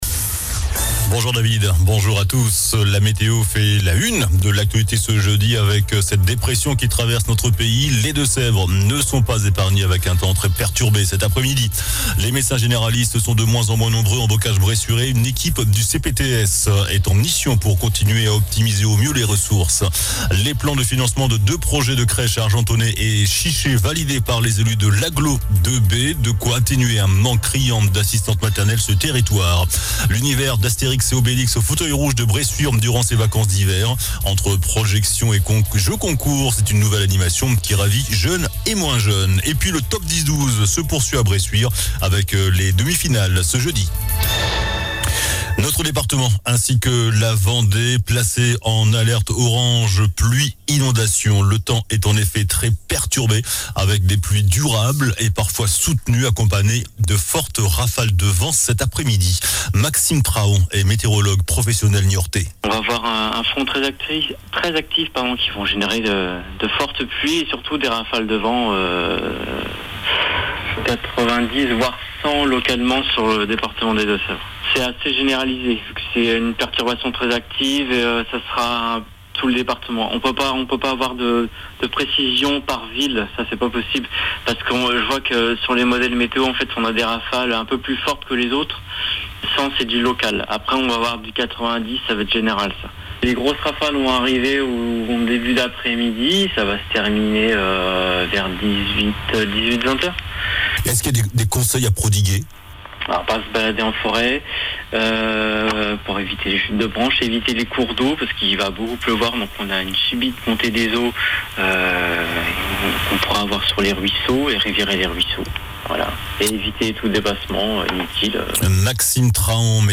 JOURNAL DU JEUDI 22 FEVRIER ( MIDI )